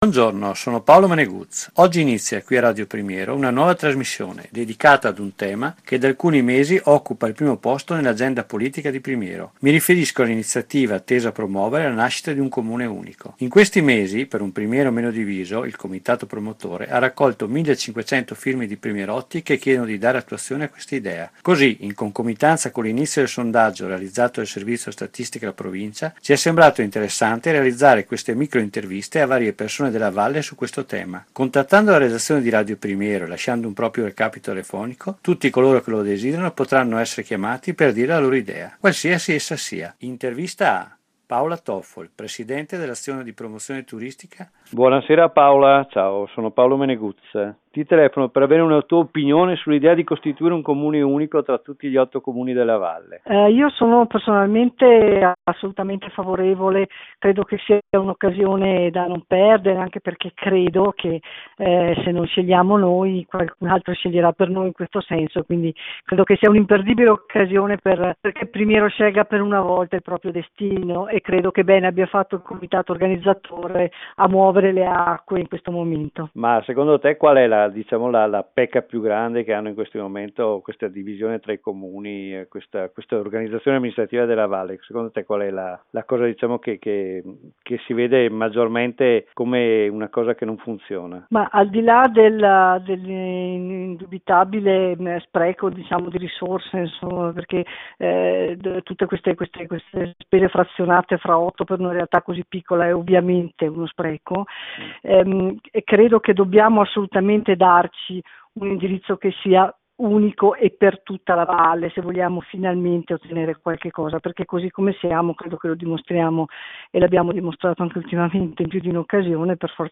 Interviste Per Un Primiero Meno Diviso